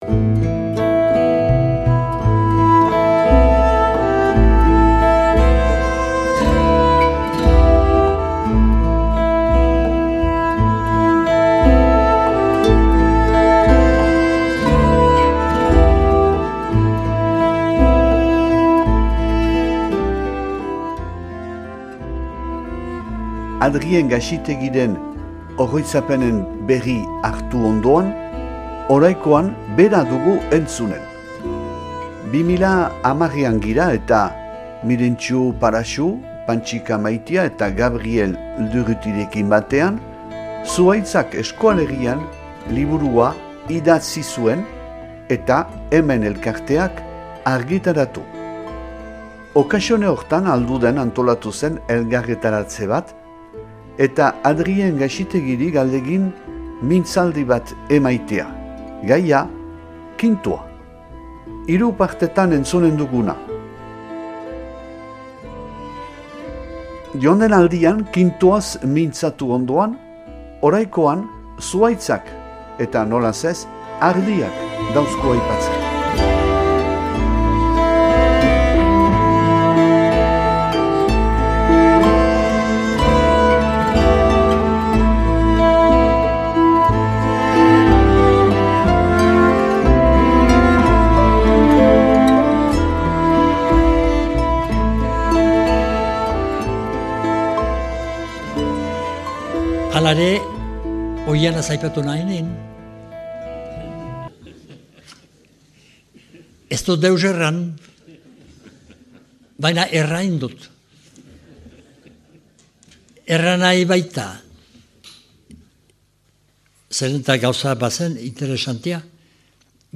irakurketa bat